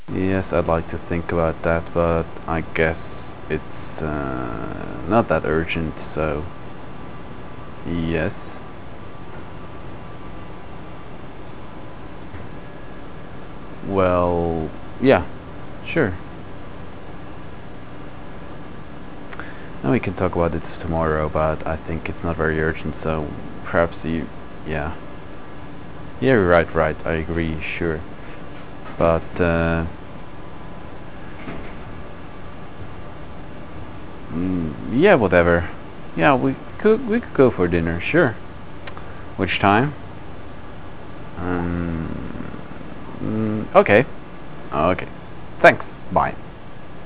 on the phone, in office
telephone1_original.au